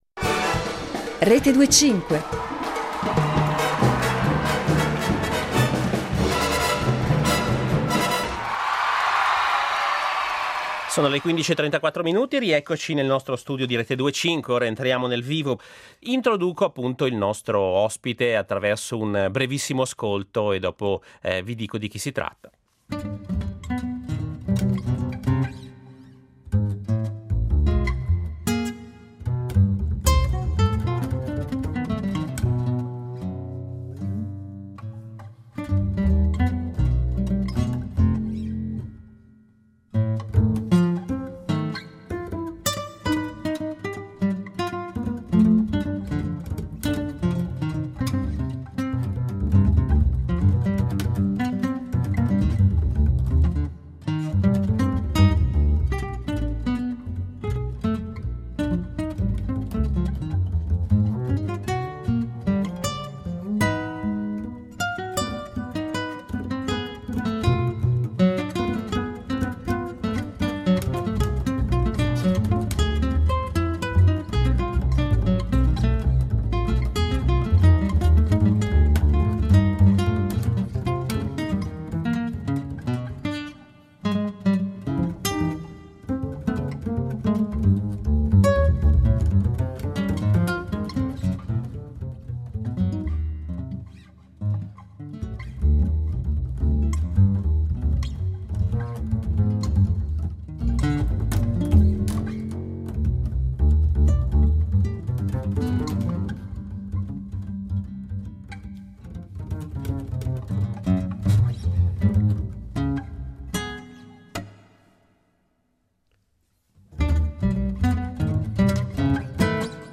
Incontro
La proposta del trio mantiene intatte le caratteristiche di questo pregevole musicista: la cantabilità delle melodie, la bellezza delle improvvisazioni e l’interesse per la poliritmia.